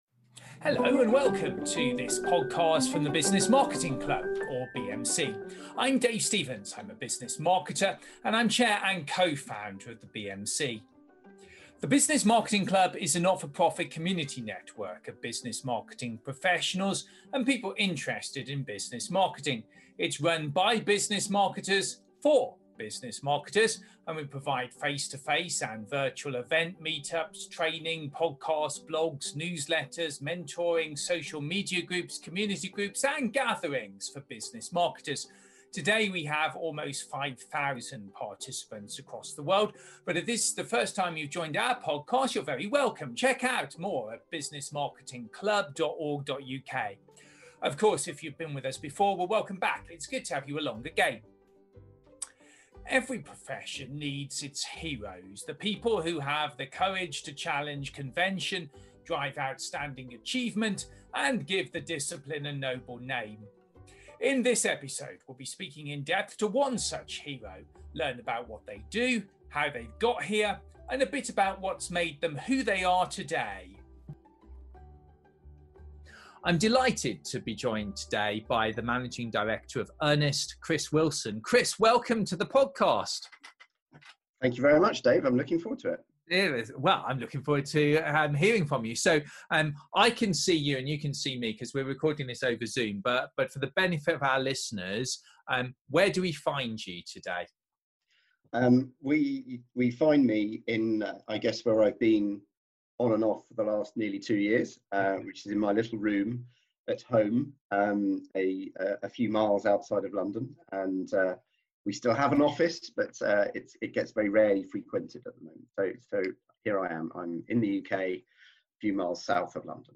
Continuing a series of in-depth interviews with some of Business Marketing’s heroes.